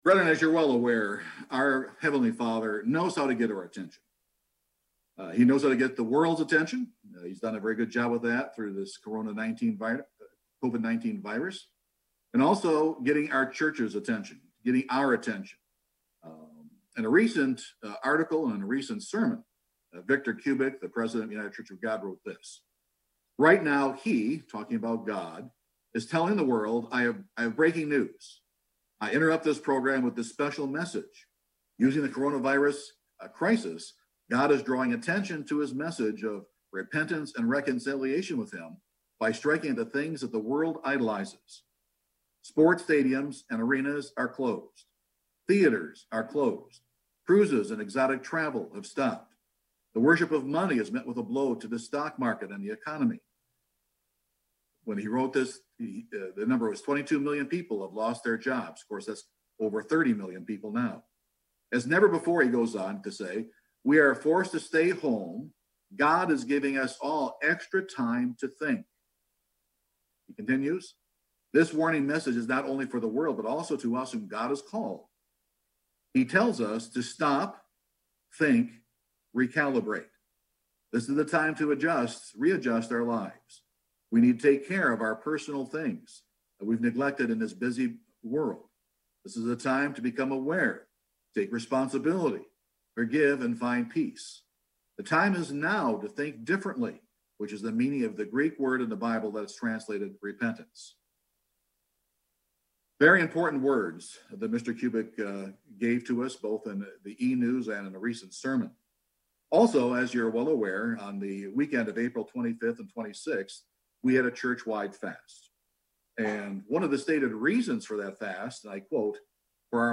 This sermon answers those questions.